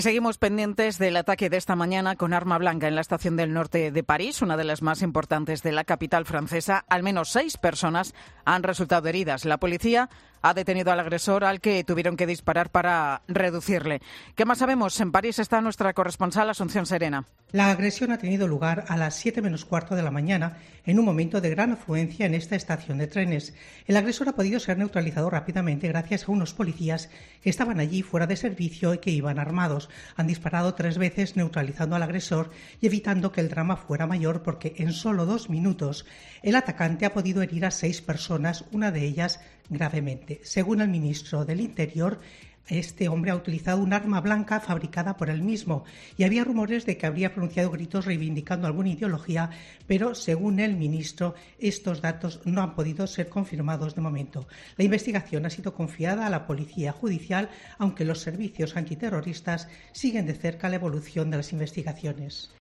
La Policía investiga el ataque ocurrido esta mañana en París. Crónica